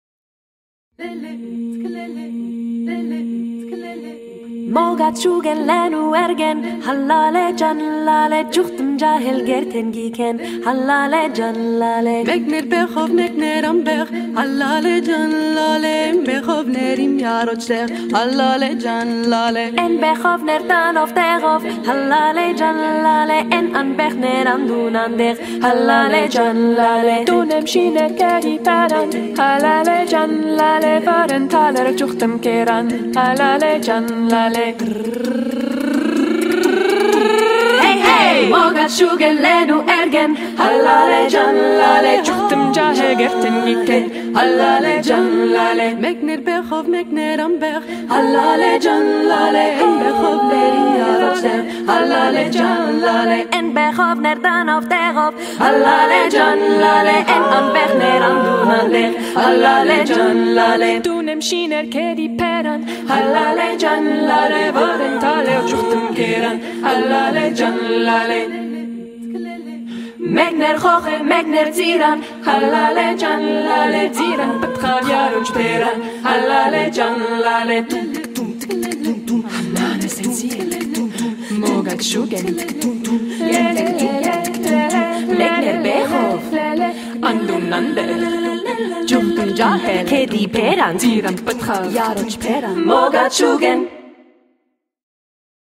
Listen to another version ● Listen to Zulal Folk Trio ’s short, but sweet, a cappella rendition of this tune below .